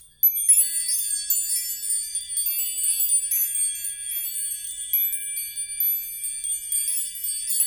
Index of /90_sSampleCDs/Roland L-CD701/PRC_Wind chimes/PRC_Windchimes